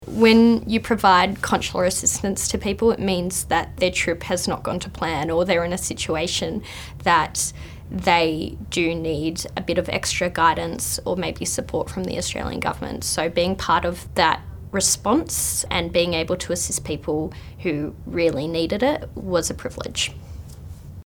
a consular officer